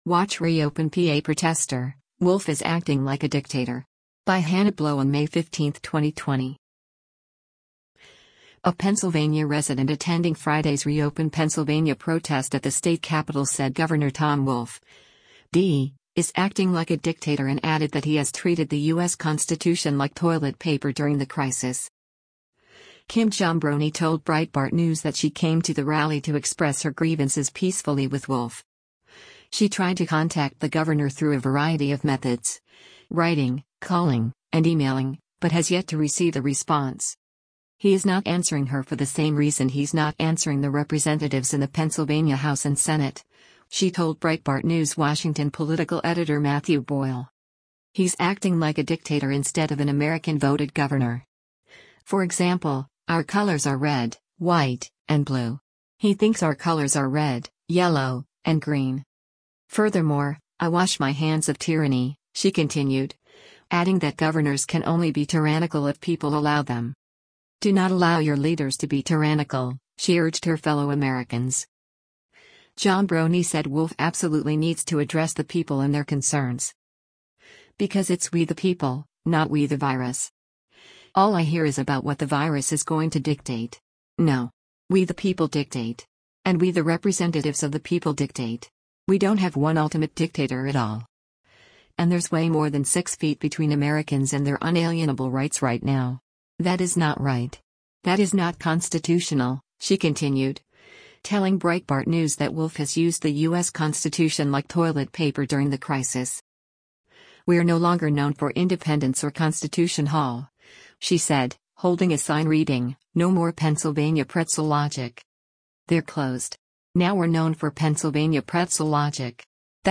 A Pennsylvania resident attending Friday’s Reopen Pennsylvania protest at the state Capitol  said Gov. Tom Wolf (D) is acting “like a dictator” and added that he has treated the U.S. Constitution like “toilet paper” during the crisis.